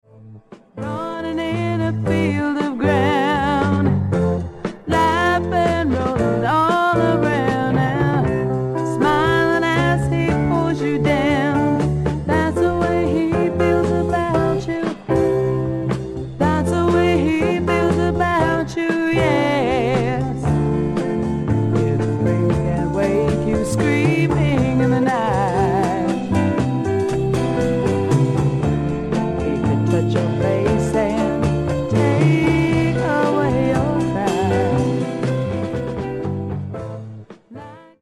SOFT ROCK / PSYCHEDELIC POP